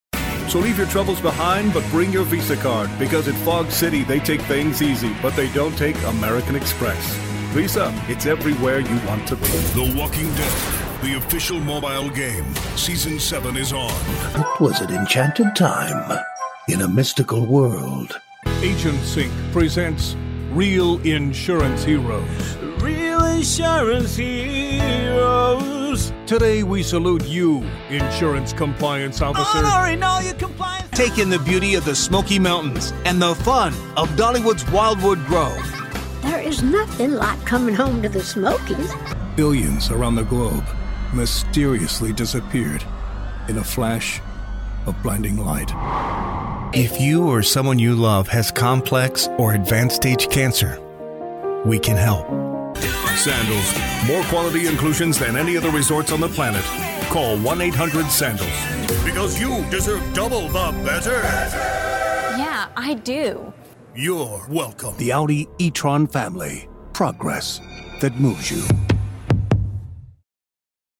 Englisch (Amerikanisch)
Kommerziell, Unverwechselbar, Zuverlässig
Unternehmensvideo